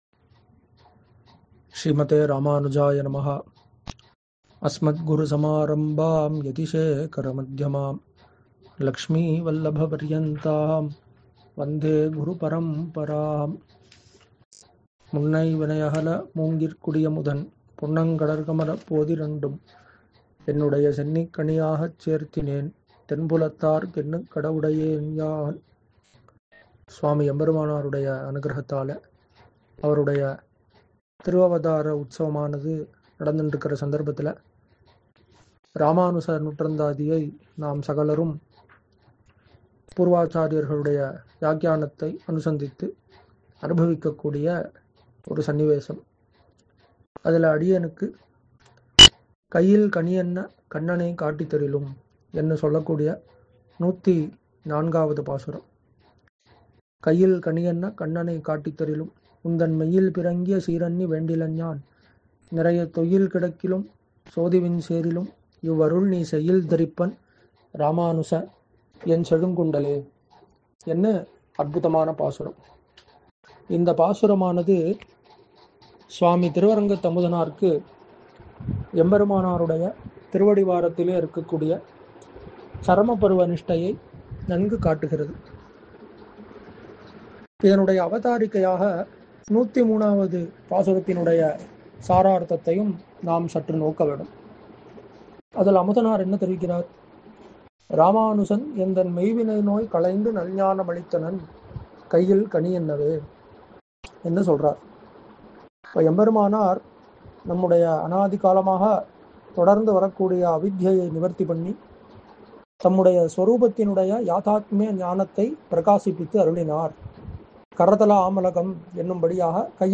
சித்திரை எம்பெருமானார் உபன்யாசத் தொடரில் – உந்தன் மெய்யில் பிறங்கிய சீர்! – என்னும் தலைப்பில் —